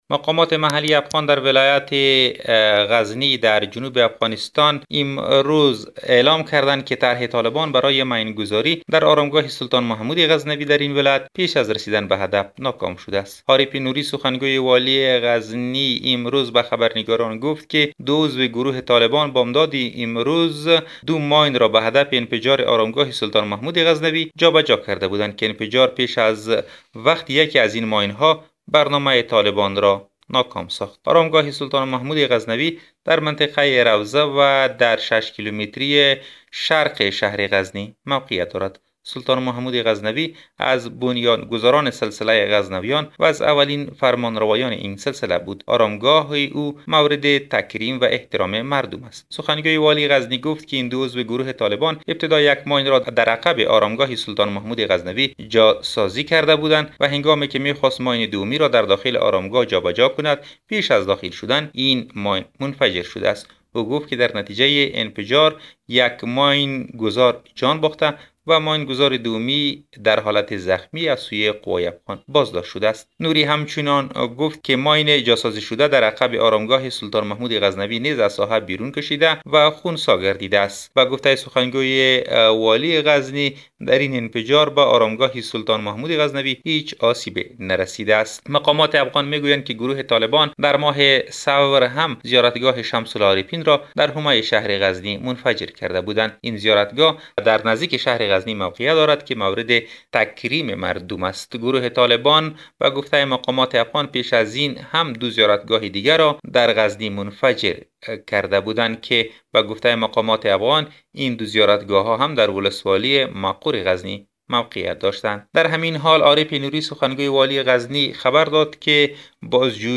جزئیات در گزارش خبرنگار رادیودری: